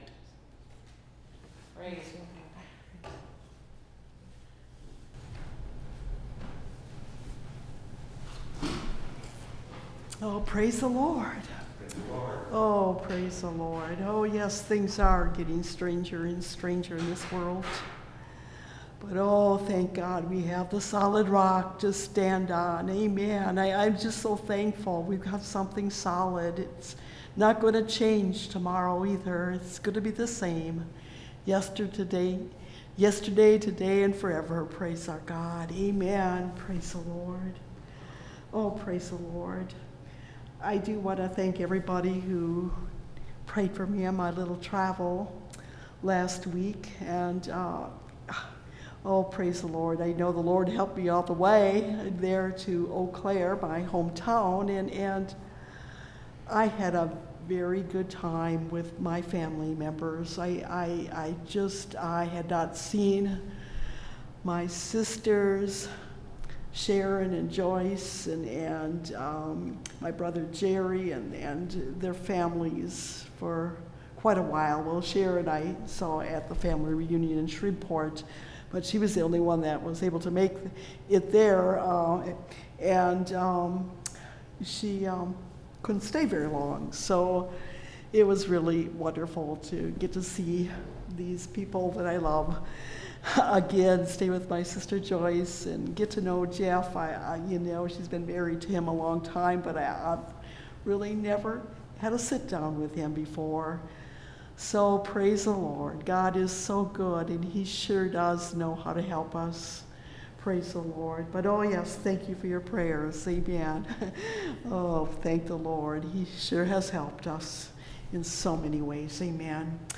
They Turned The World Upside Down – Part 2 (Message Audio) – Last Trumpet Ministries – Truth Tabernacle – Sermon Library
Service Type: Wednesday Night Bible Study